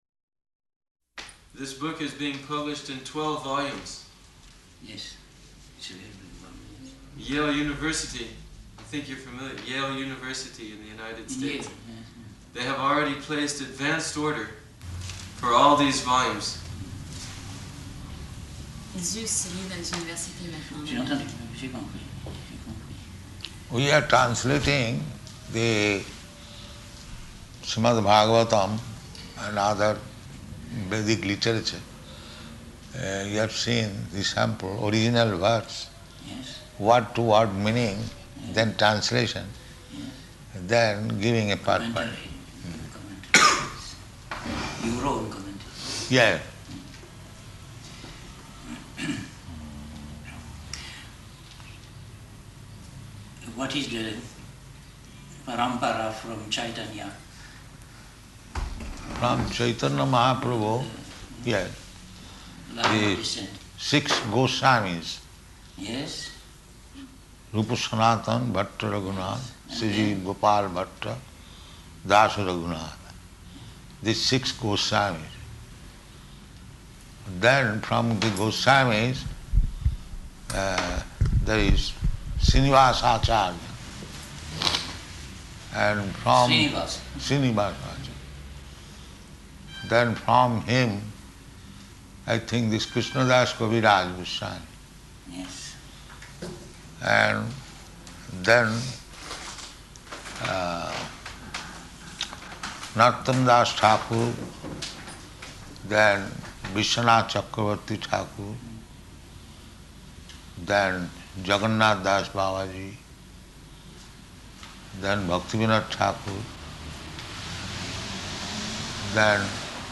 -- Type: Conversation Dated: June 14th 1974 Location: Paris Audio file